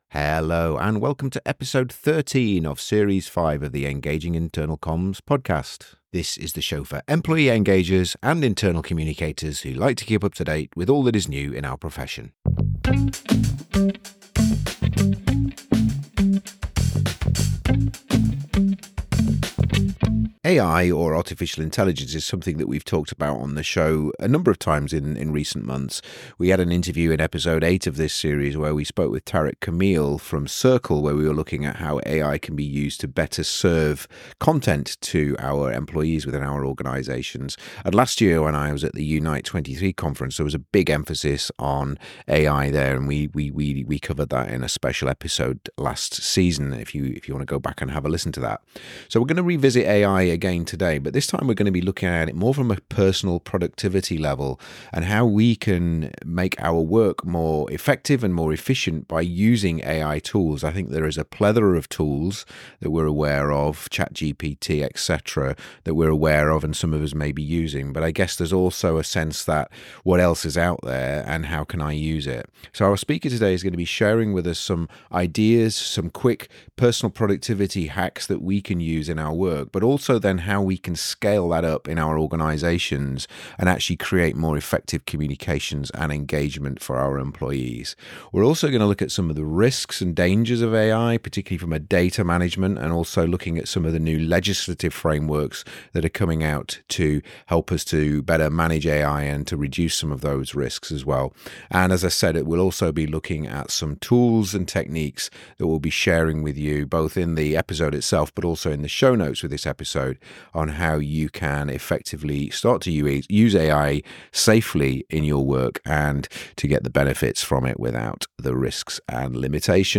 We take a deep dive into the transformative realm of artificial intelligence (AI) and its profound impact on personal productivity and organisational effectiveness. In this thought-provoking interview, we peel back the layers of AI's potential to revolutionise the way we work and communicate.